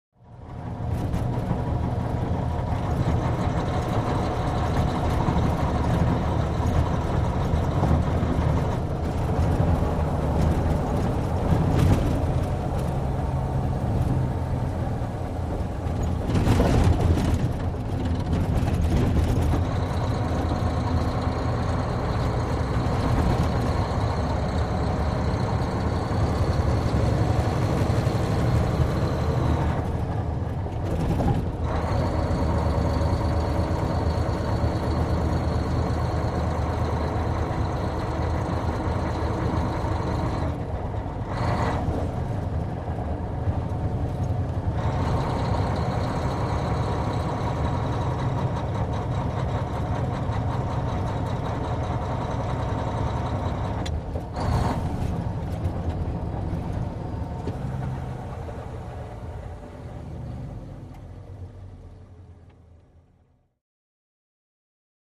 tr_macktruck_driving_03_hpx
Mack truck interior point of view of driving and shifting gears. Vehicles, Truck Engine, Motor